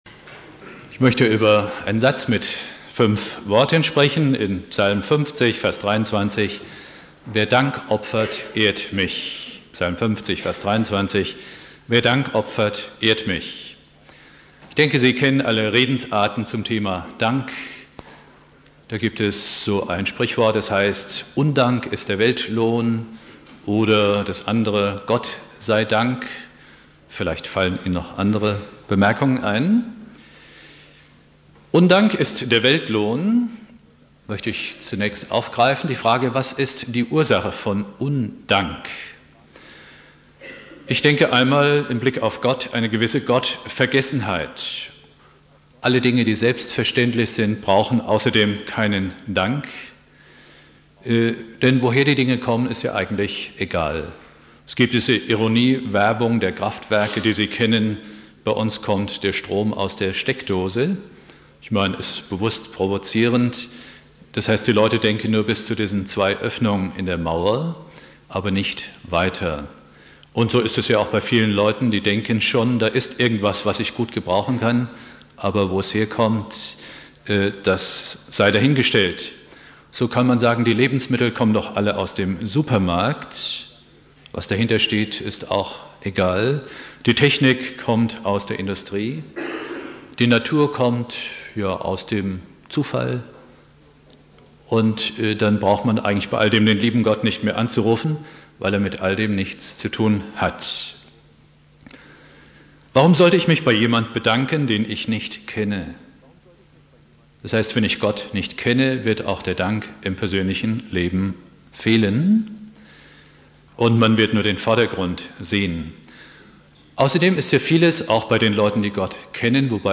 Predigt
Silvester Prediger